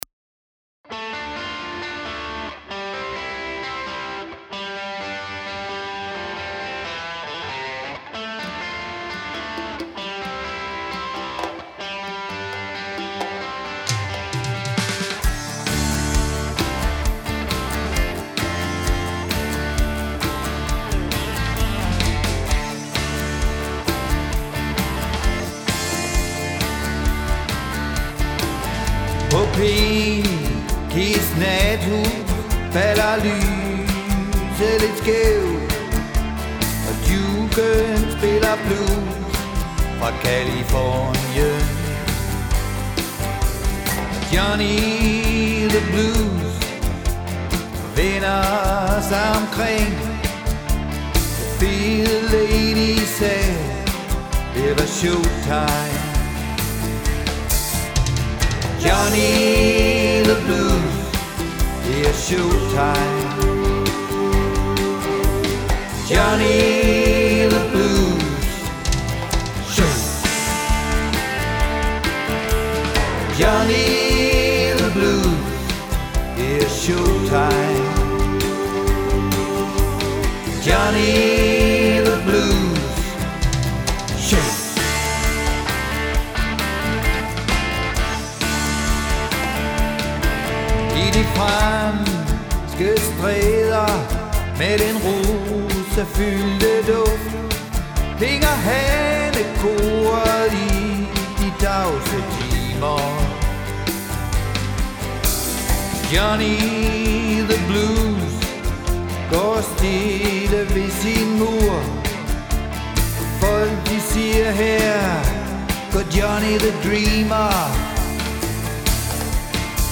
Trommer, percussion og sang.
Bas og sang.
Guitar, keyboards og sang.
El-guitar og sang.